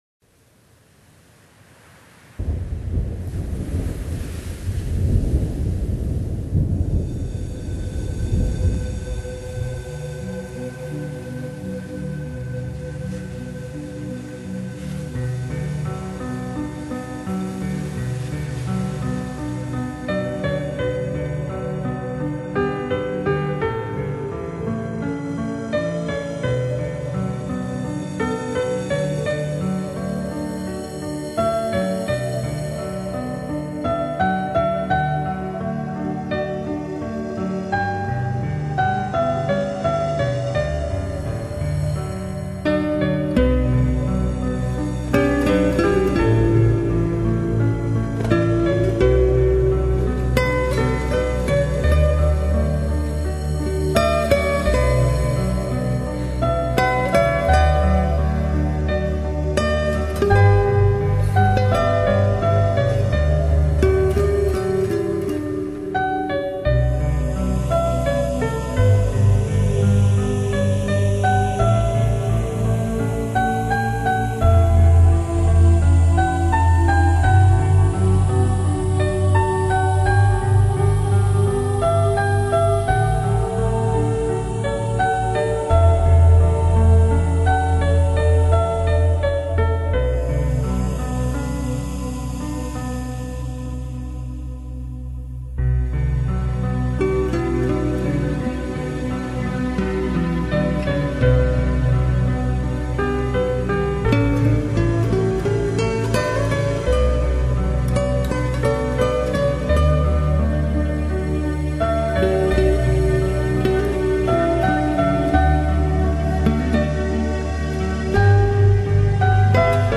合成器铺陈的氛围，教人心醉神迷
的长笛吹奏